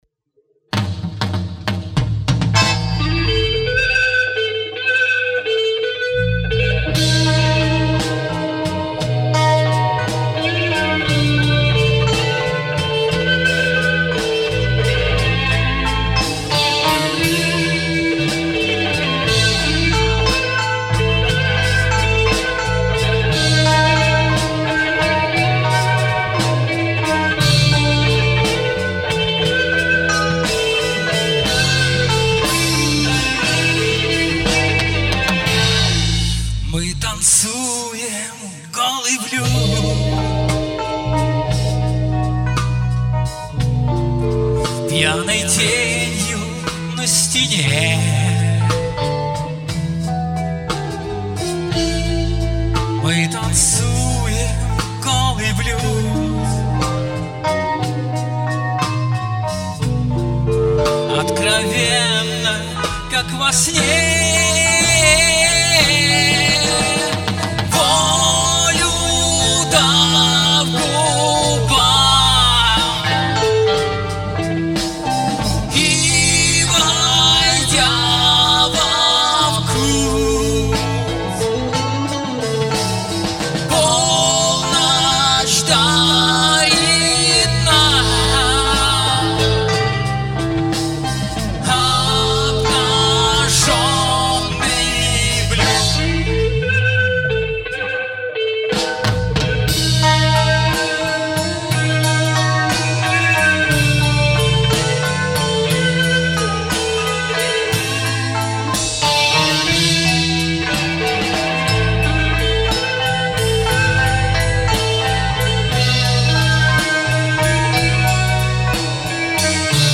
АНОНС ! 12 ноября в Лисичанске состоялся блюз-рок-панк фестиваль честной живой музыки.